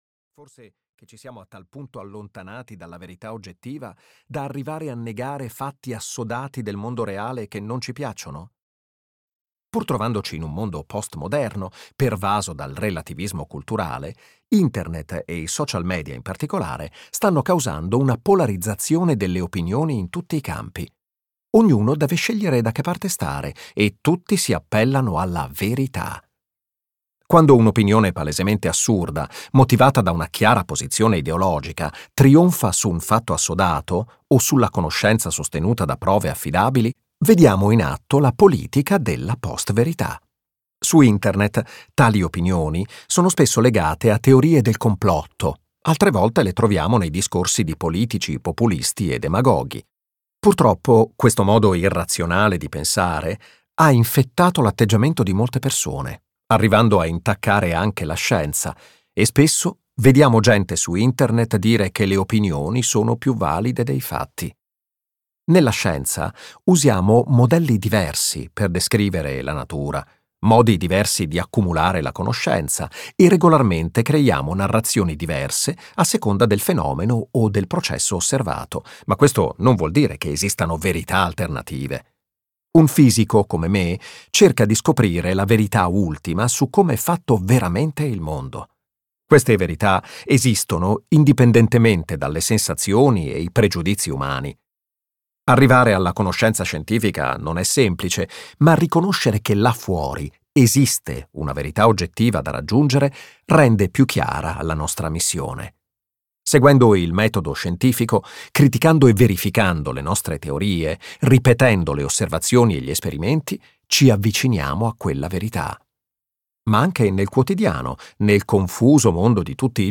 "Le gioie della scienza" di Jim Al-Khalili - Audiolibro digitale - AUDIOLIBRI LIQUIDI - Il Libraio